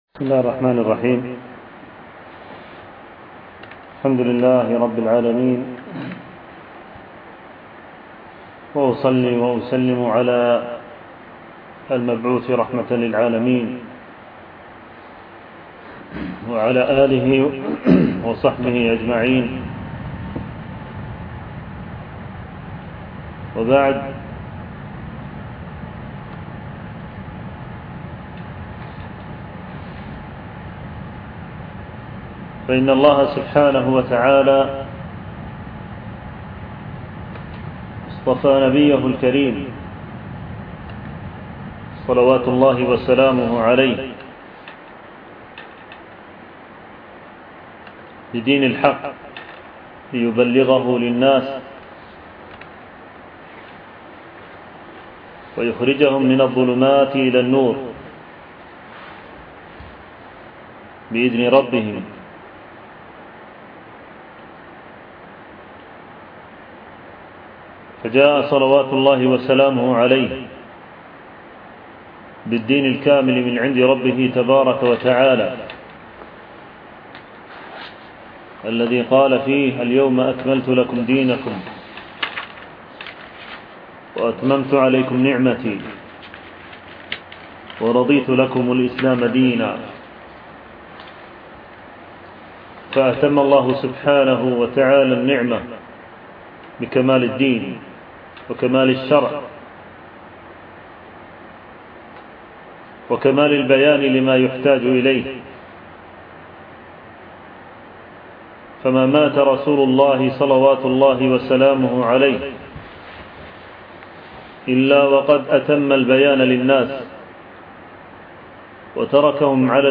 فقه السلف في التعامل مع أهل البدع و أهل المعاصي الألبوم: محاضرات المدة